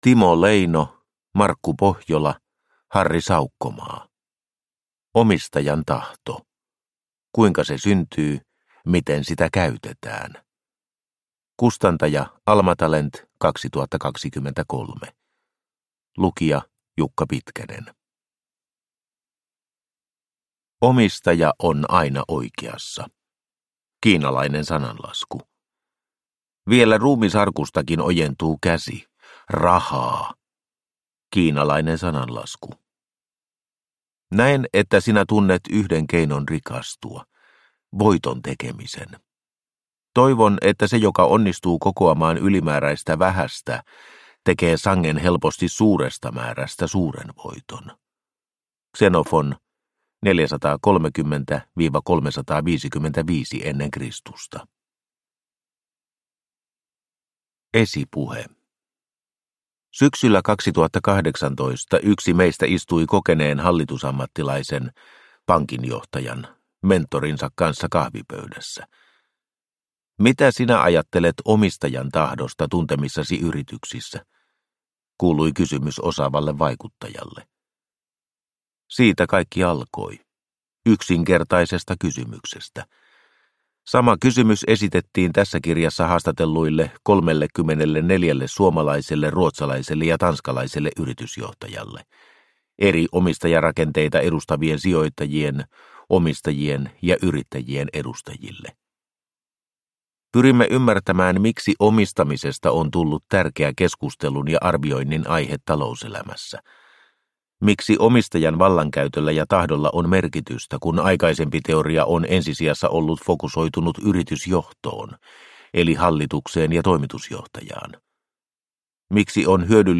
Omistajan tahto – Ljudbok – Laddas ner